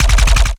GUNAuto_RPU1 C Burst_05_SFRMS_SCIWPNS.wav